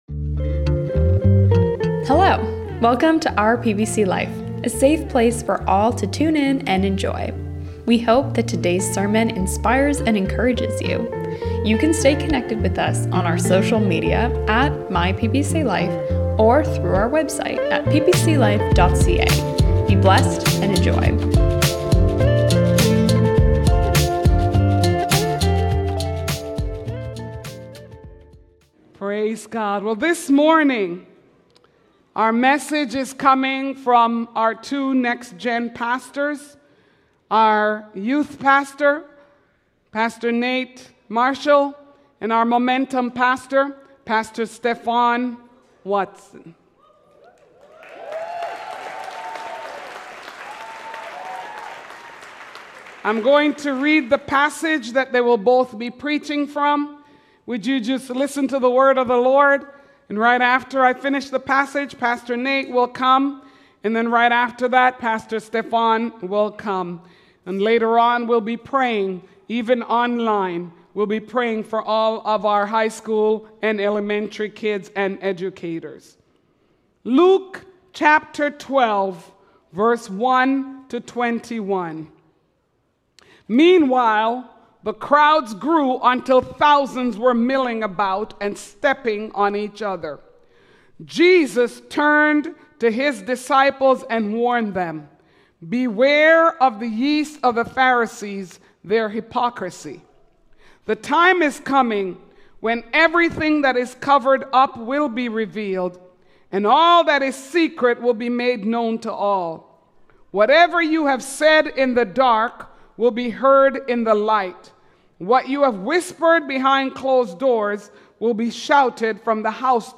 Our Next Generation Pastors bring two encrouaging messages that work together as one word about embracing the true riches of God.